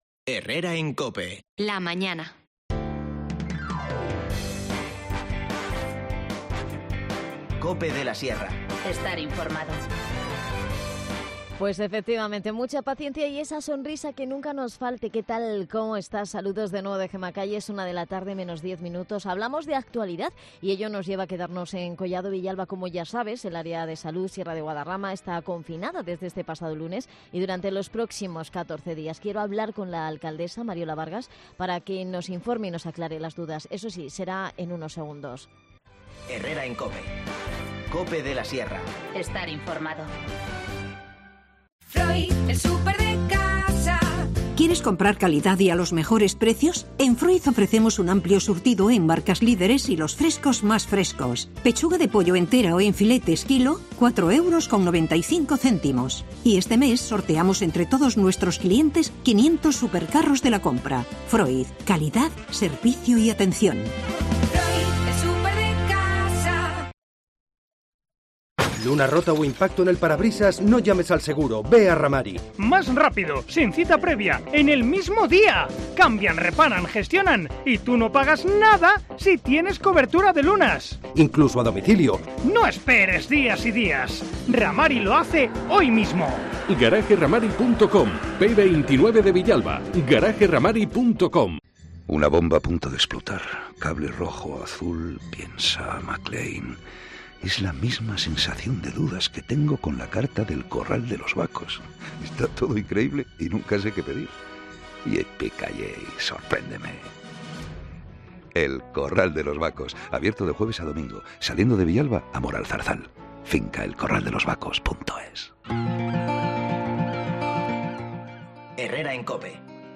El Ayuntamiento de Collado Villalba emprende un plan de información a lo vecinos con motivo del confinamiento del Área de Salud Sierra de Guadarrama. Nos lo cuenta la alcaldesa, Mariola Vargas